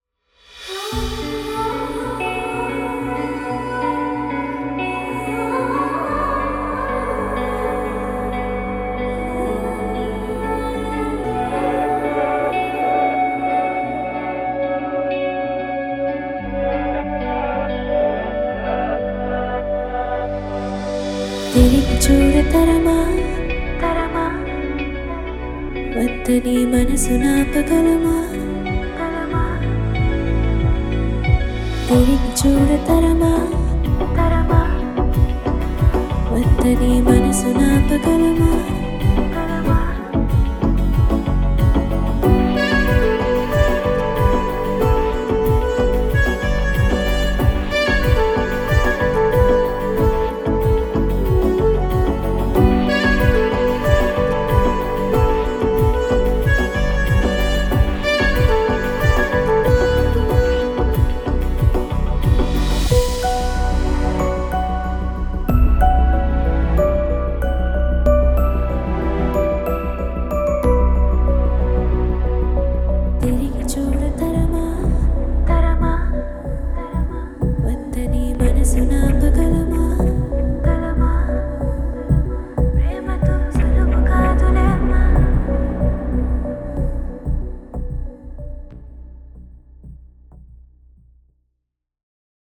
in HQ without dialogues and disturbances
female version